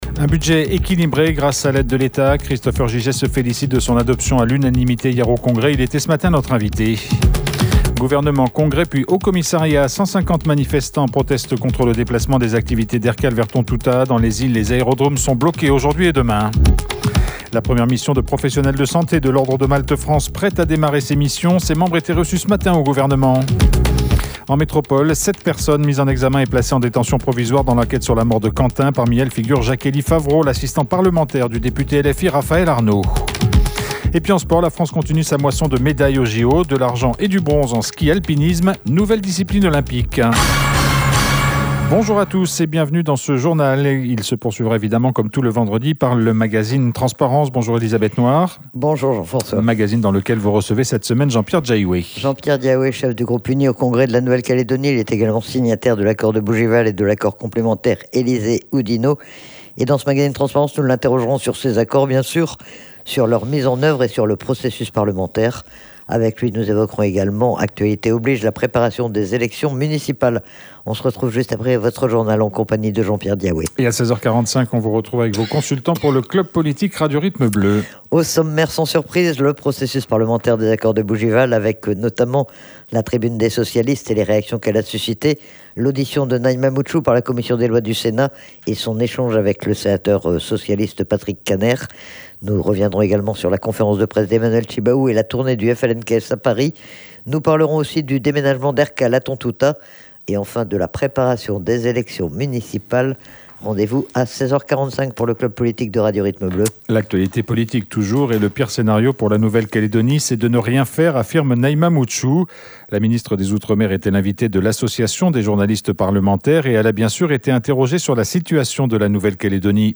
Il a bien sûr été interrogé sur ces accords, sur leur mise en œuvre et sur le processus parlementaire. Mais aussi sur la préparation des élections municipales.